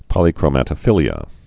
(pŏlē-krō-mătə-fĭlē-ə) also pol·y·chro·mo·phil·i·a (-krōmə-fĭlē-ə)